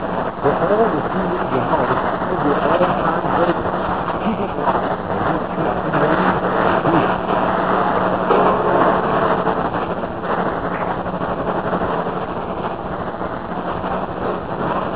This page contains DX Clips from the 2005 DX season!